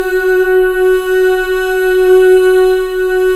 Index of /90_sSampleCDs/Club-50 - Foundations Roland/VOX_xFemale Ooz/VOX_xFm Ooz 1 S